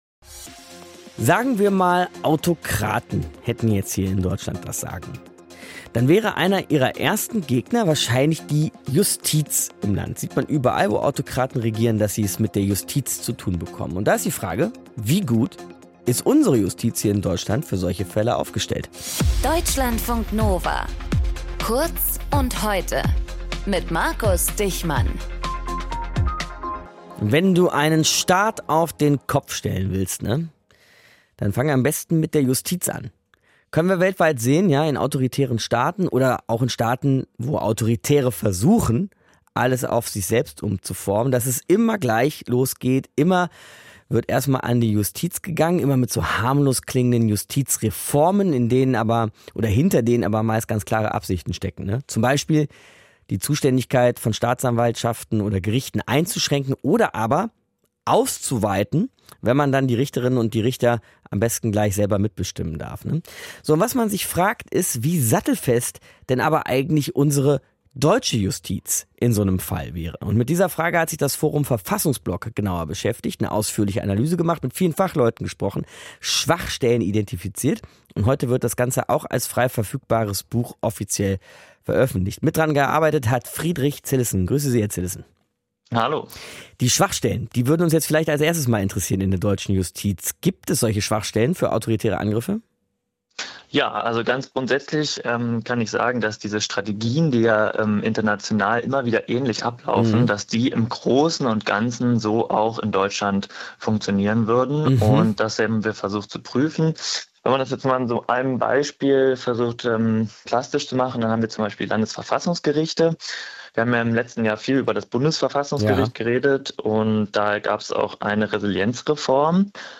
Moderator:
Gesprächspartner: